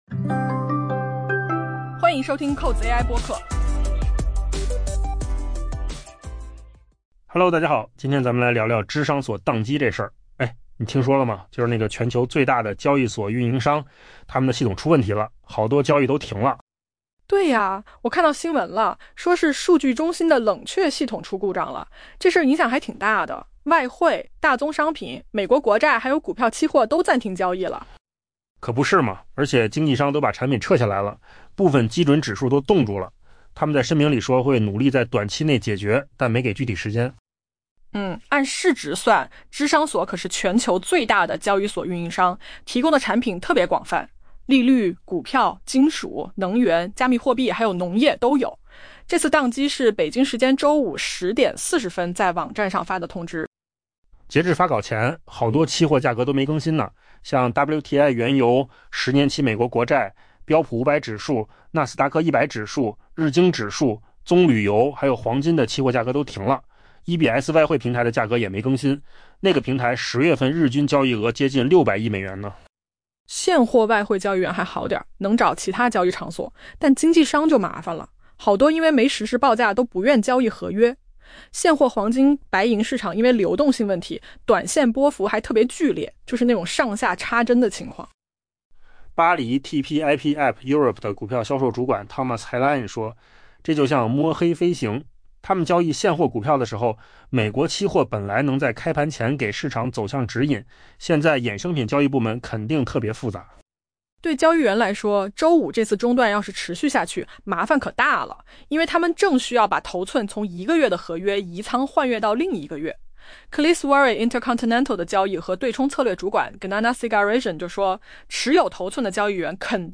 【文章来源：金十数据】AI播客：换个方
AI 播客：换个方式听新闻 下载 mp3 音频由扣子空间生成 全球最大交易所运营商芝商所 （CME） 发生宕机，导致其热门货币平台以及涵盖外汇、大宗商品、美国国债和股票的期货交易暂停，随着经纪商撤下产品，部分基准指数陷入冻结。